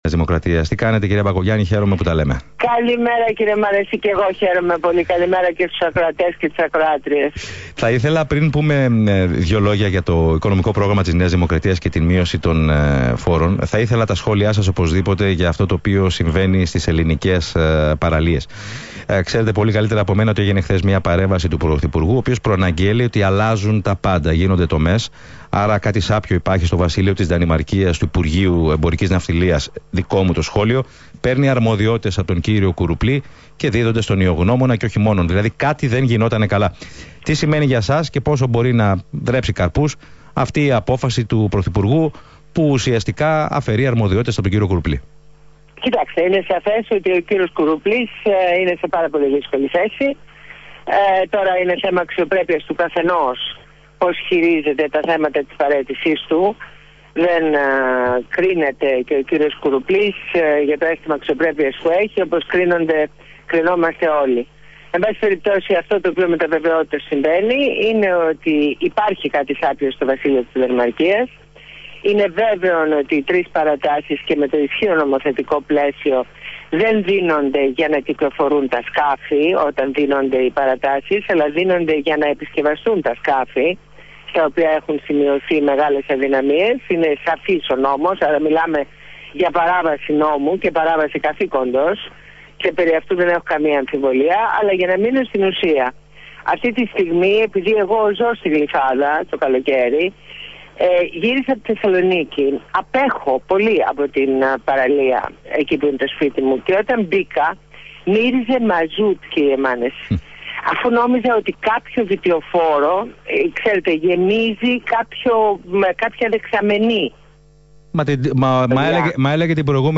Συνέντευξη στο ραδιόφωνο του ALPHA